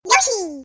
yoshi_Long_Jump_Take_3.ogg